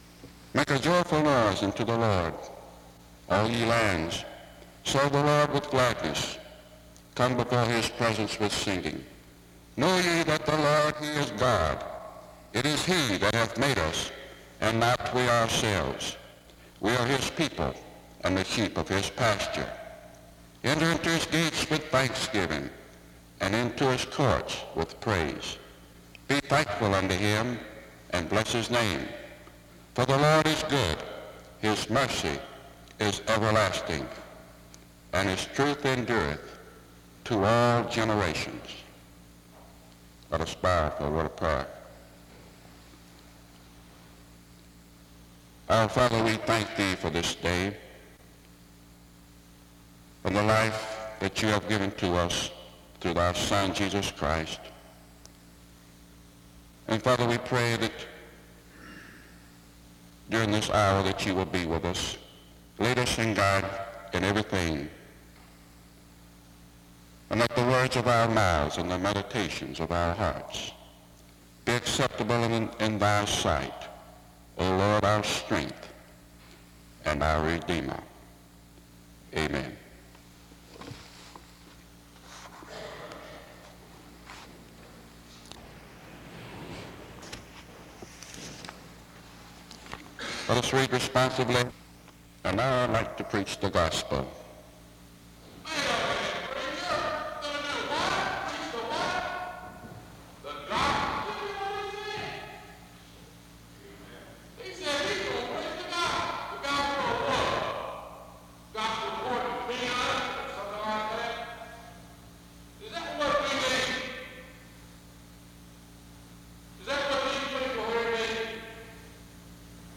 SEBTS Chapel - Student Service March 26, 1969
Download .mp3 Description The service begins with a scripture reading and prayer from 0:00-1:20. A message and short skit on the importance of the gospel is given from 1:30-11:00. A closing prayer is offered from 11:24-12:11. This service was organized by the Student Coordinating Council.